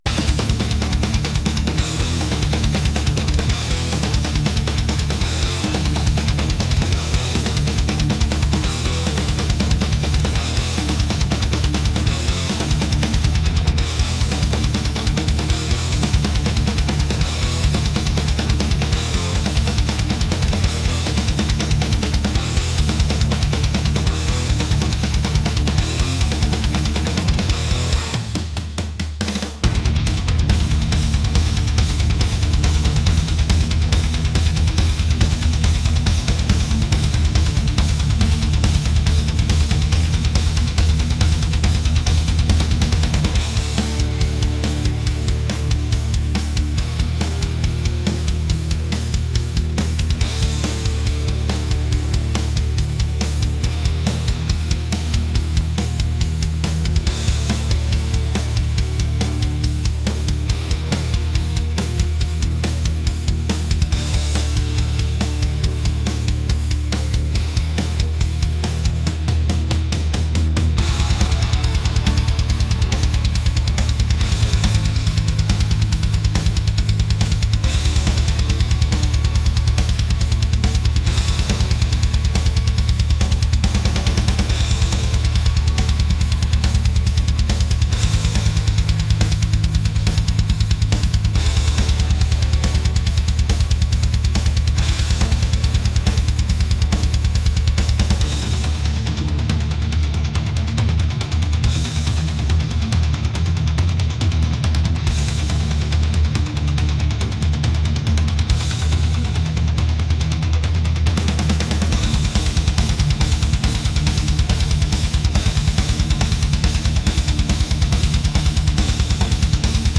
aggressive | metal